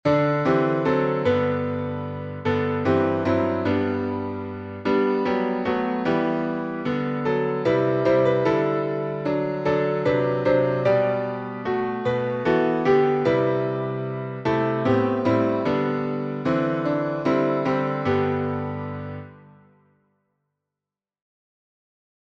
Key signature: G major (1 sharp) Time signature: 3/4 Meter: 8.8.8.8. (L.M.) harm.